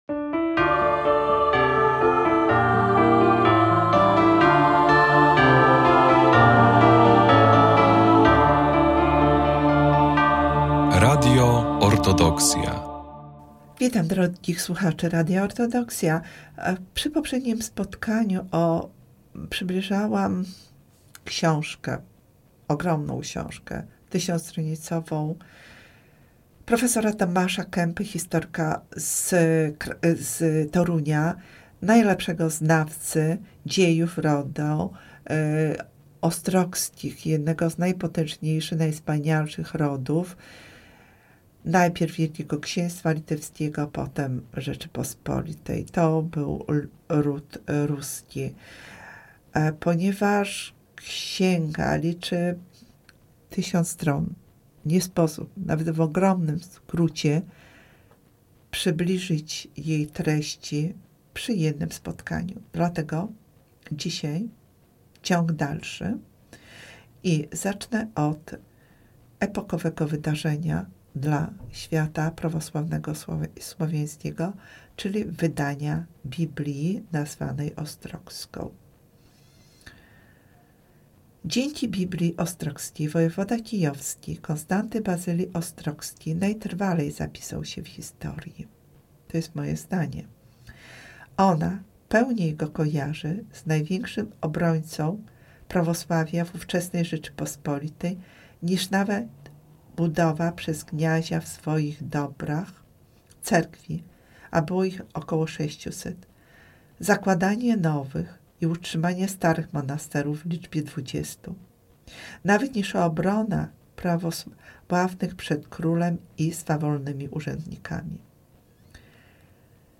Prowadząca prezentuje i omawia wybrane lektury warte uwagi, często związane z kulturą, historią i duchowością, dzieląc się refleksjami i czytelniczymi rekomendacjami.